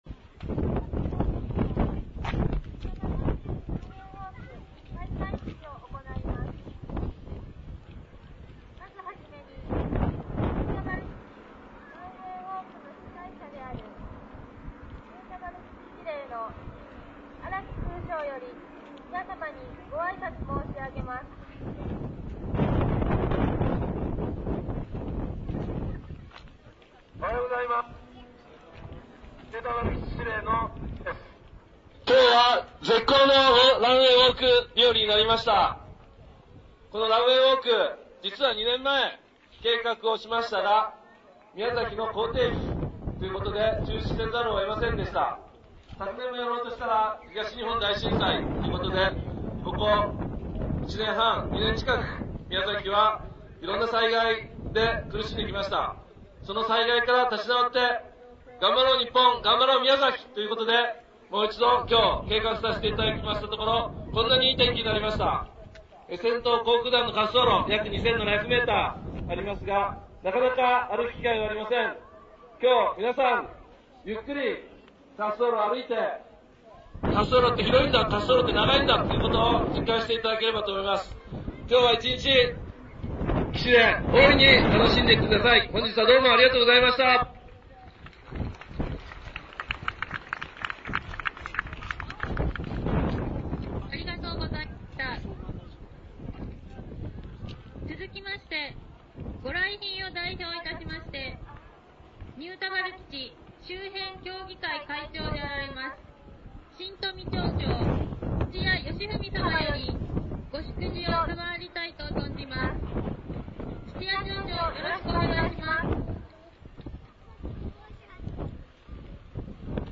協力会を代表して新富町長の挨拶でしたがピンボケしてたー。
強風で風切り音がうるさいです、あと近くのスピーカーが途中でオンに
なったんでいきなり音が大きくなります。